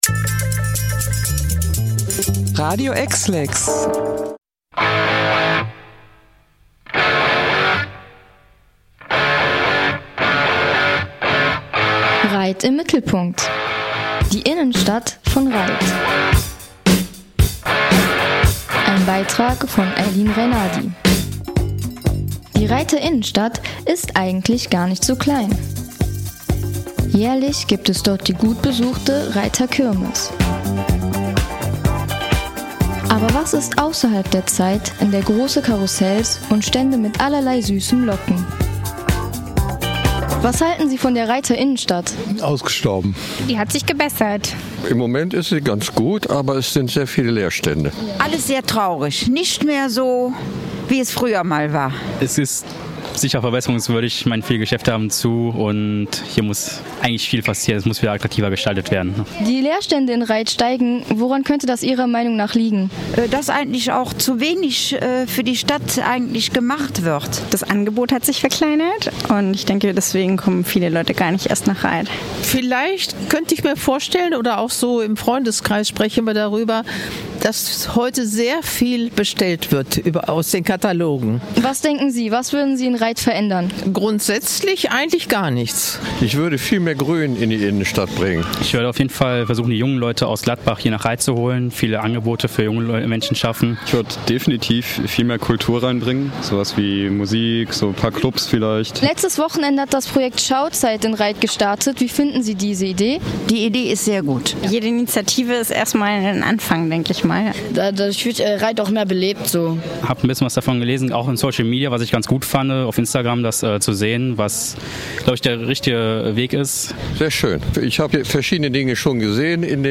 Umfrage-Rheydter-Innenstadt-Komplett-AR-TB.mp3